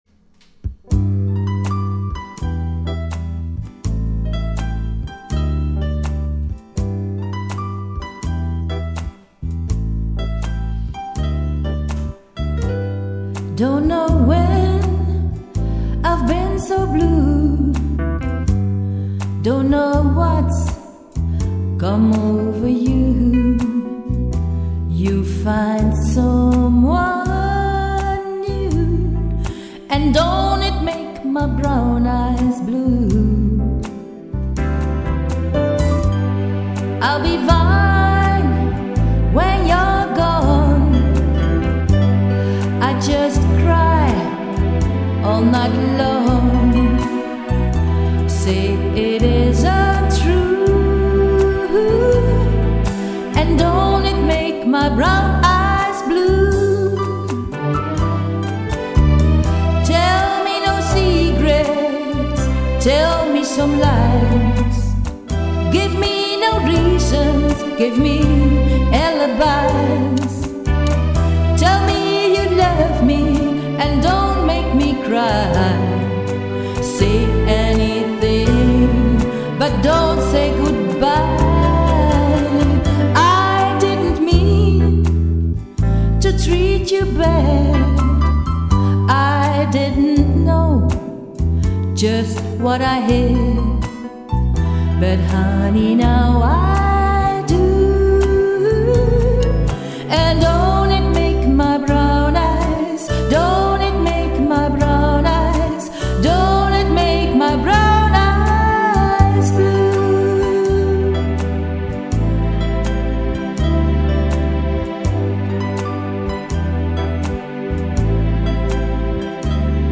Keyboard
Gitarre
Percussion